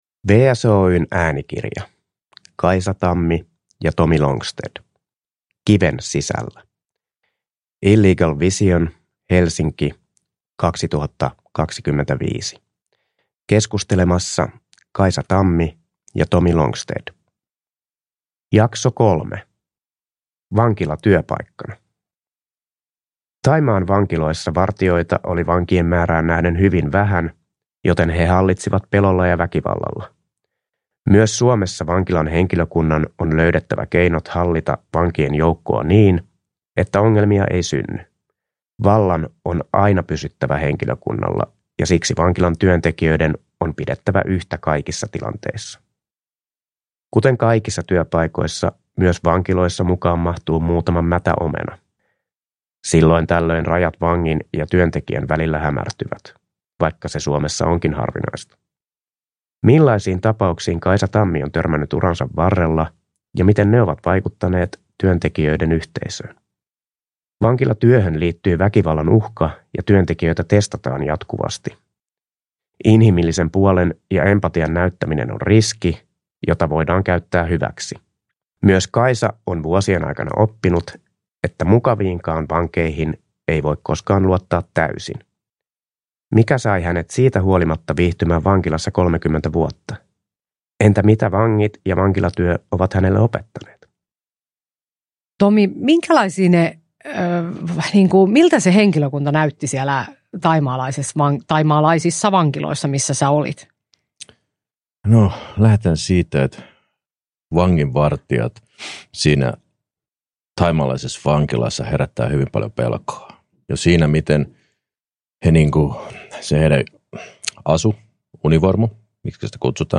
Kiven sisällä, osa 3 – Ljudbok